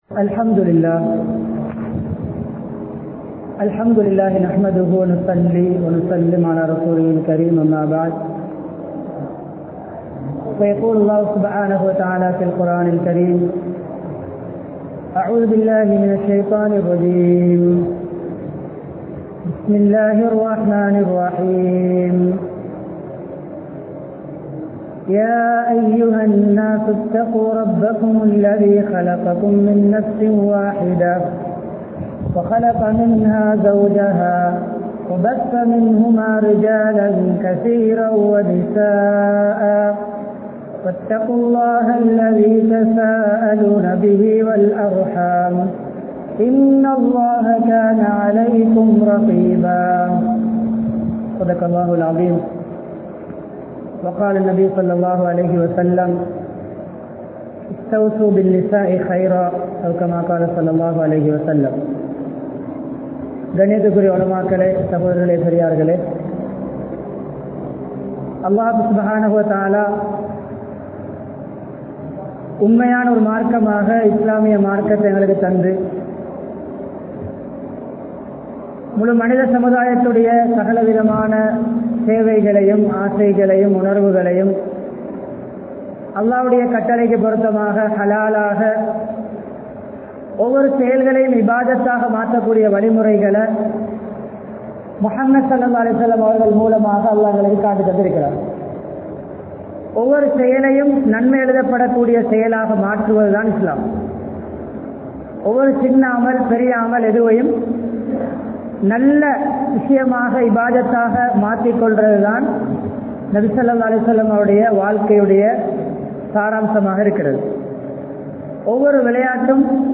Seeraana Kanvan Manaivu Uravu (சீரான கணவன் மணைவி உறவு) | Audio Bayans | All Ceylon Muslim Youth Community | Addalaichenai